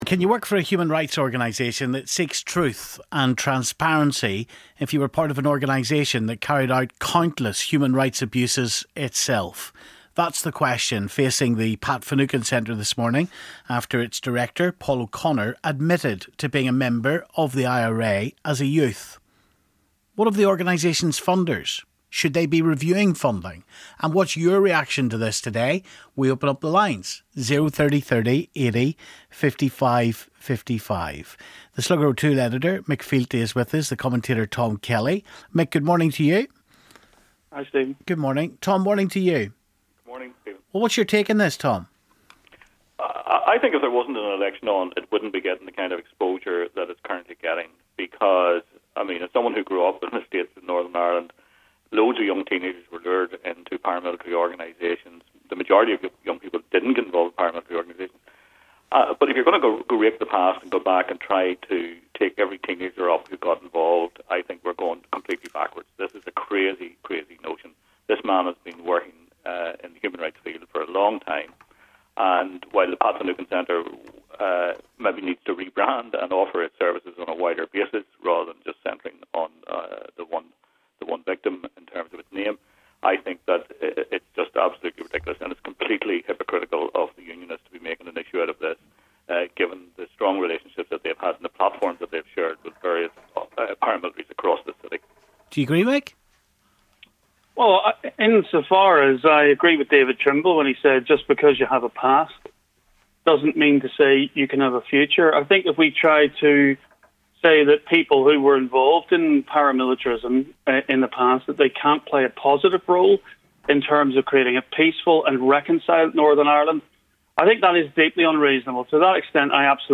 Joining Stephen on air